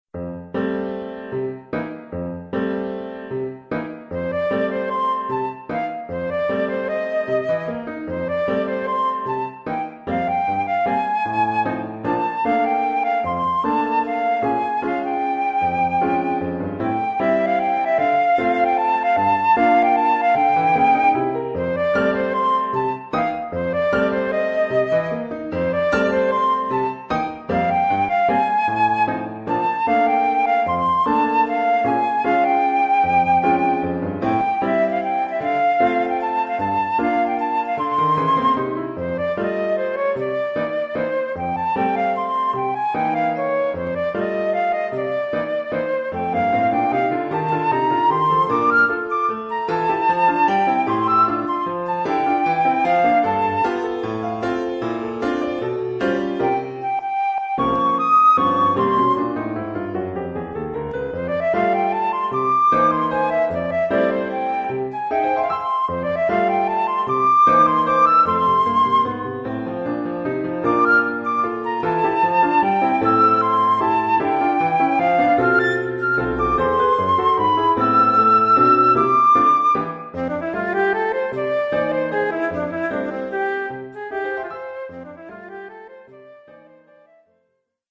Flûte traversière
Oeuvre pour flûte et piano.